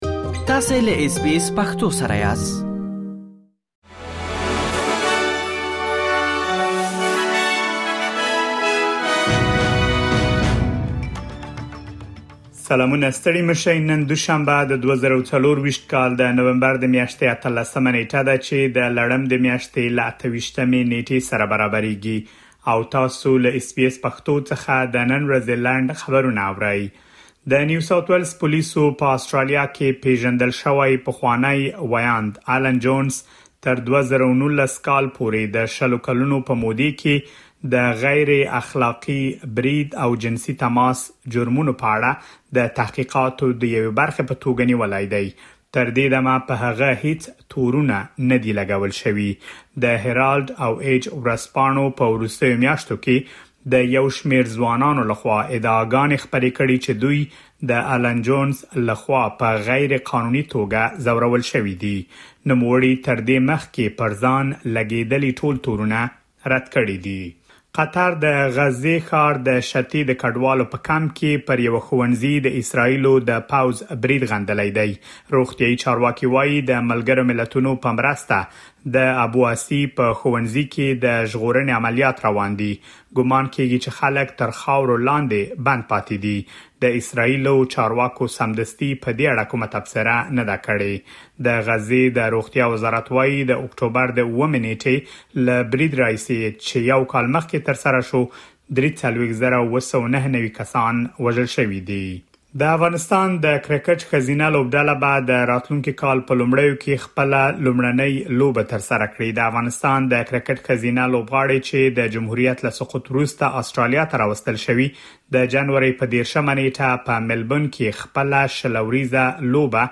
د اس بي اس پښتو د نن ورځې لنډ خبرونه |۱۸ نومبر ۲۰۲۴